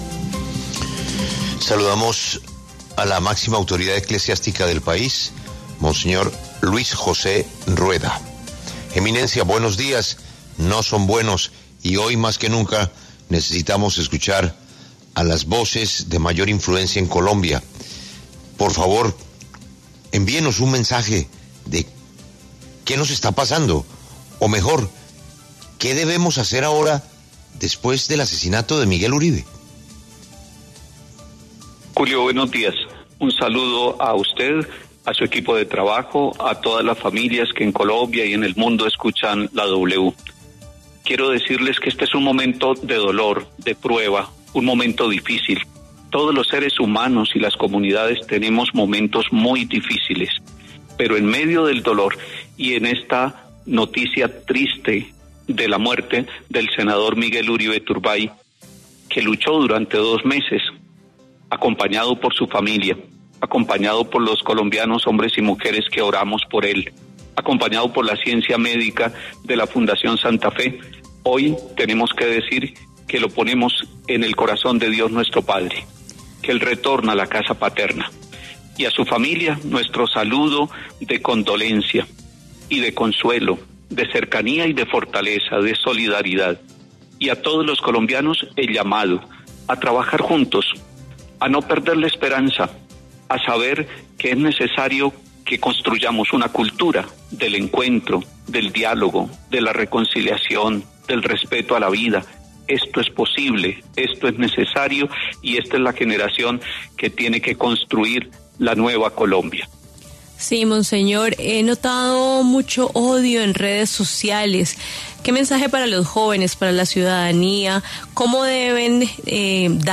En diálogo con La W, monseñor Luis José Rueda, arzobispo de Bogotá, se refirió a la muerte del senador y precandidato presidencial Miguel Uribe Turbay, quien fue víctima de un atentado el pasado 7 de junio.